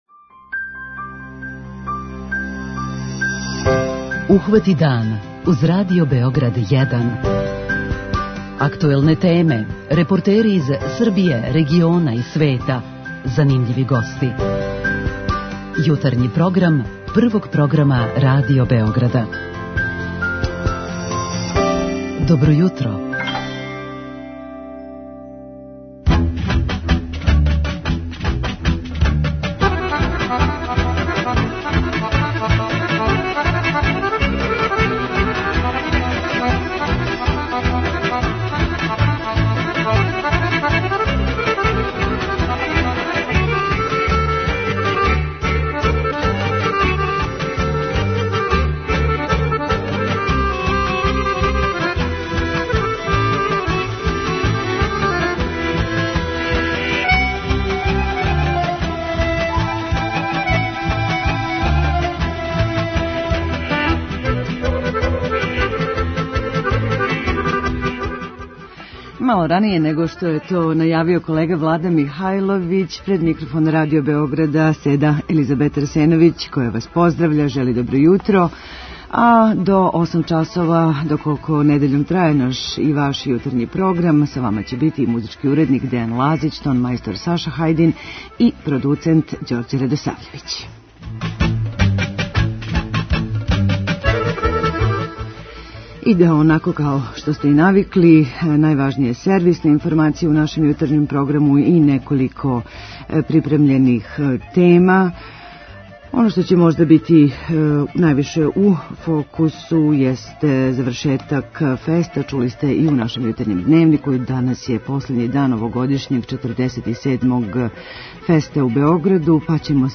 У лежернијем, викенд издању нашег Јутарњег програма, уз обиље добре народне музике из богатог фонда Радио Београда, Вашој пажњи препоручујемо и неколико тема: